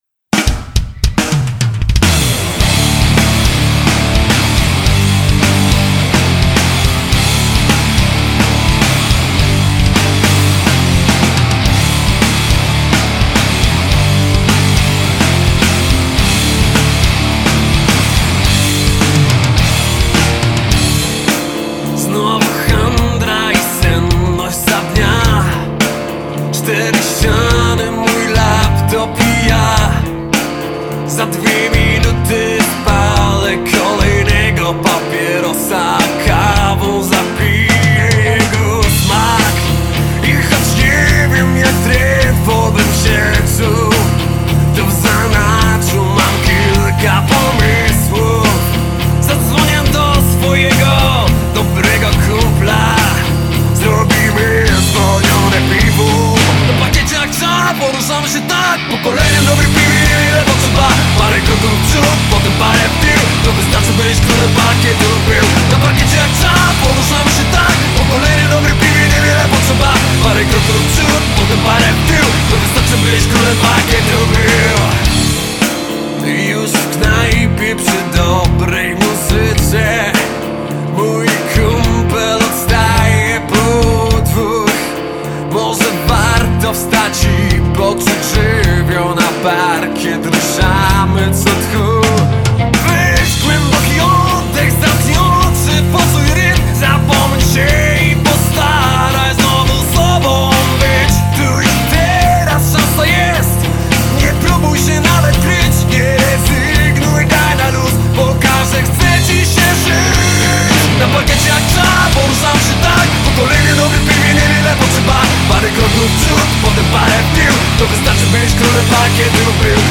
Gramy muzykę rockową.
Gatunek: Hard-Rock, Rock